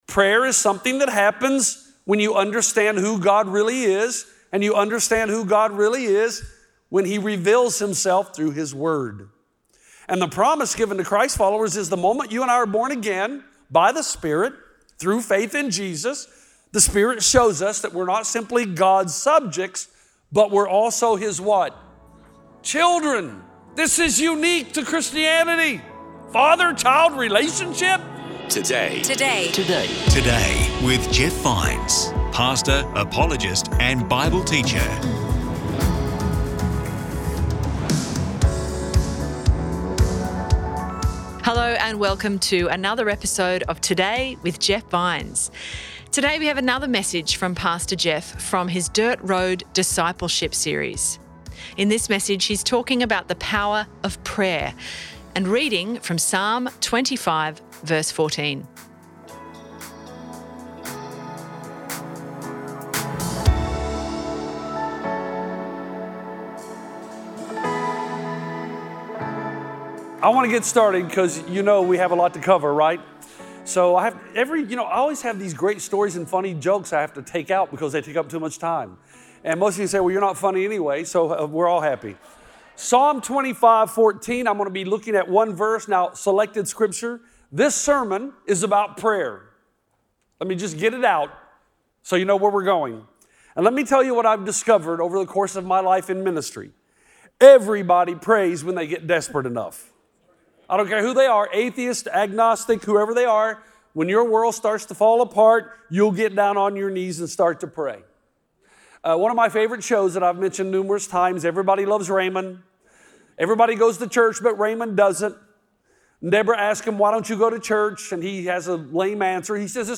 Today we have another message